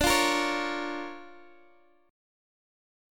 DmM7#5 chord